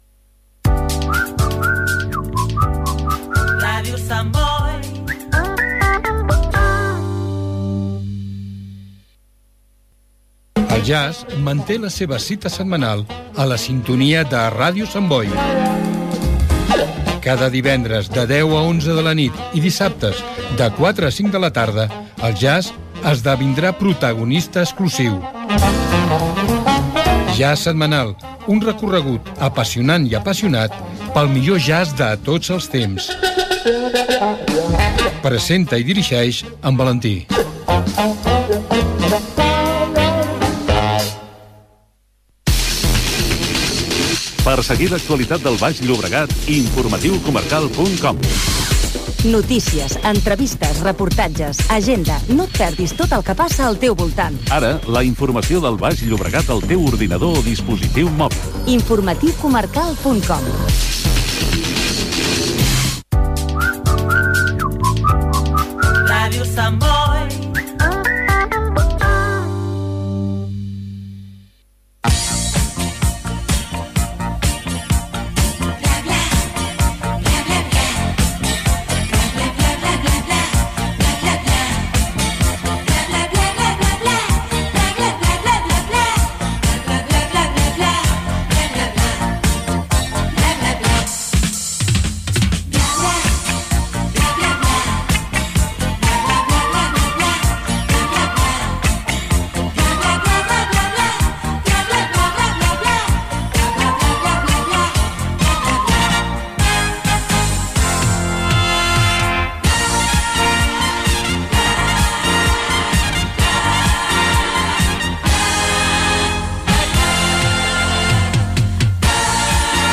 Sintonia, presentació del programa amb esment a la nova pàgina web de Ràdio Sant Boi, concurs, resum de notíces inútils
Gènere radiofònic Entreteniment